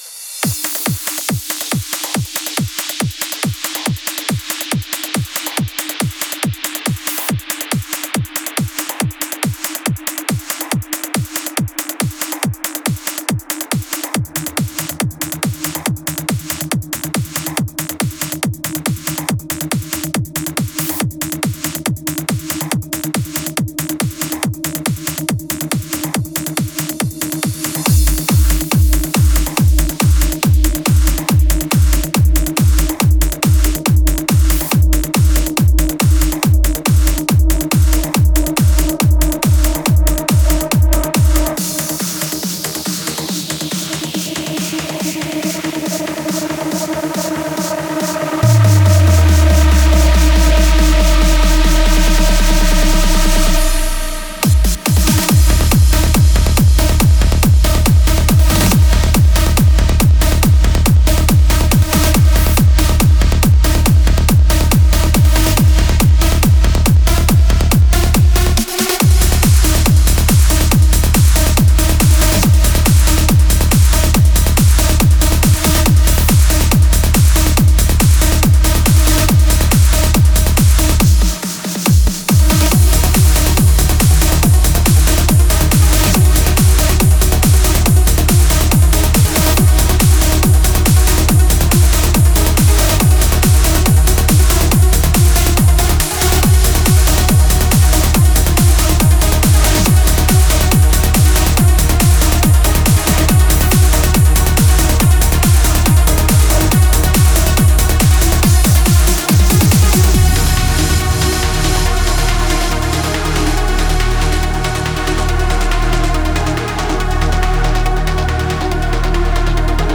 Стиль: Trance / Uplifting Trance